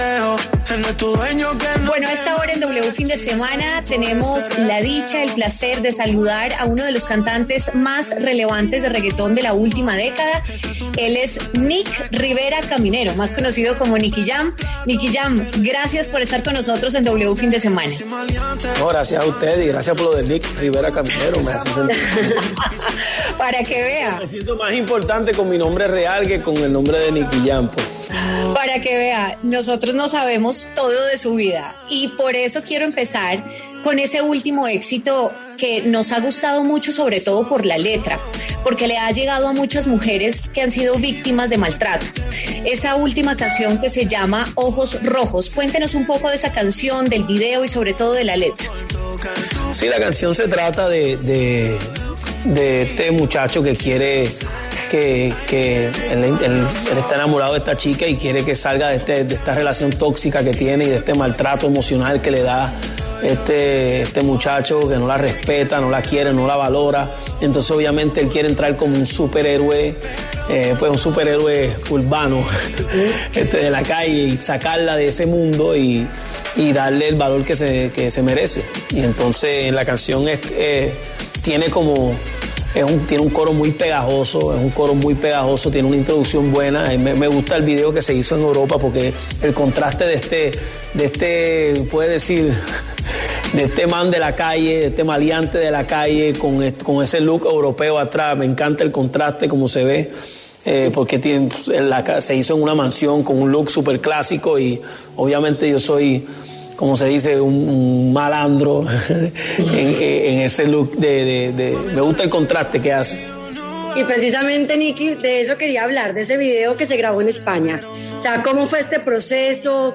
En diálogo con W Fin de Semana, el artista Nicky Jam aseguró que el género reguetón está lejos de agotarse, pues seguirá fusionándose con otros ritmos y evolucionando como lo ha hecho hasta ahora.